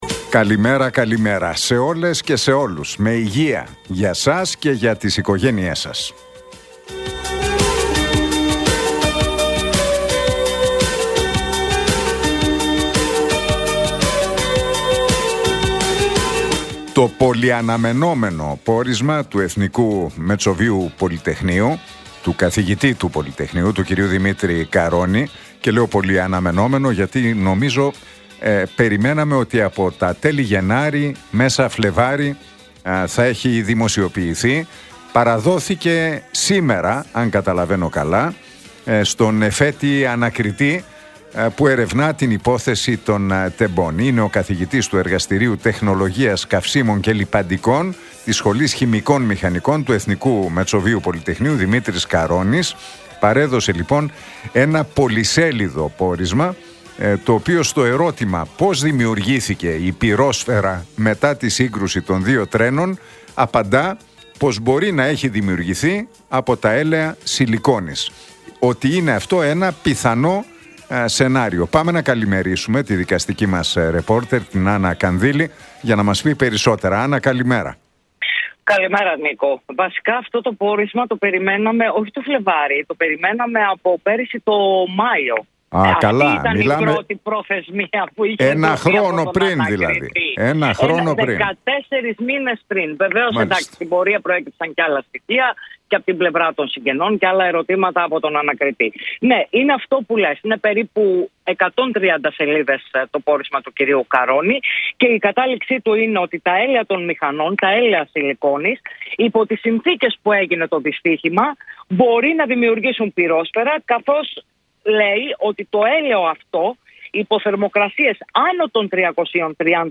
Ακούστε το σχόλιο του Νίκου Χατζηνικολάου στον ραδιοφωνικό σταθμό Realfm 97,8, την Τρίτη 13 Μαΐου 2025.